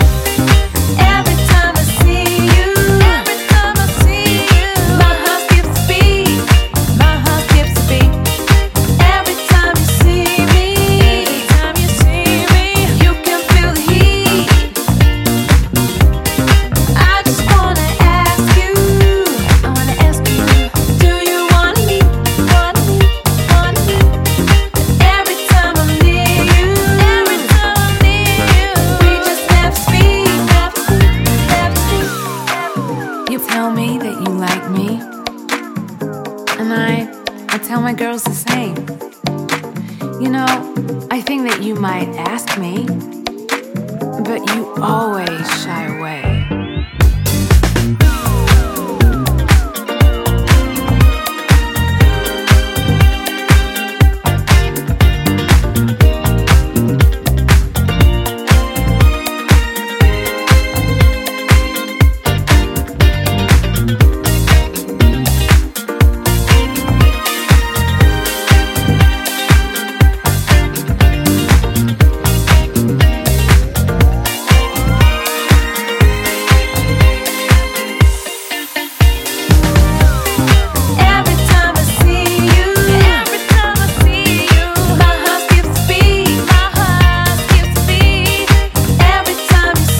(B面)は各曲ともにソウルフル＆ディスコ〜ディープ・ハウスで捨曲一切無しです。
ジャンル(スタイル) DISCO / HOUSE